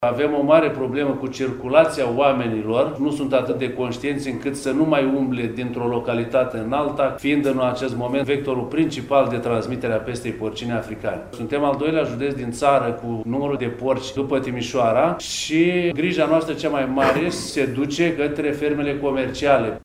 Şi judeţul Brăila este grav afectat de pesta porcină africană – peste 3.000 de animale din ferme şi gospodării au fost omorâte până acum. Şeful Direcţiei Sanitar Veterinare Brăila, Gicu Drăgan, le atrage atenţia oamenilor să respecte măsurile de siguranţă impuse pentru limitarea răspândirii virusului: